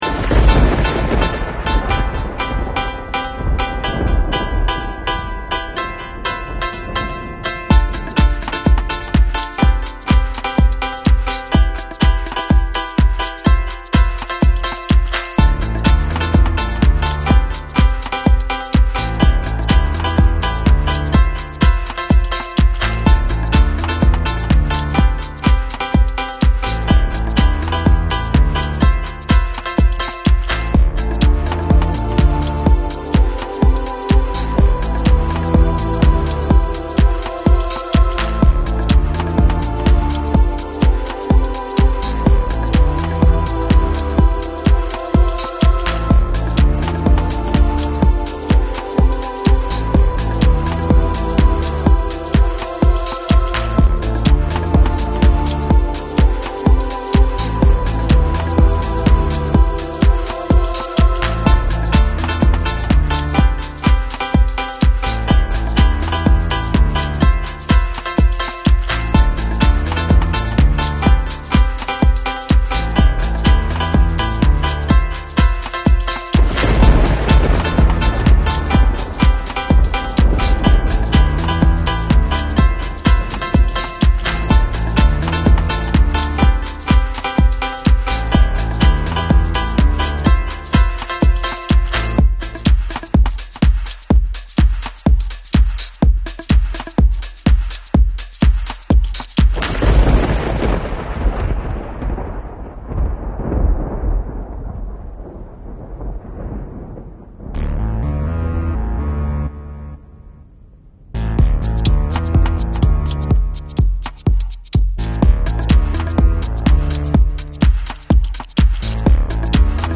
03:47 Techno 3.6 MB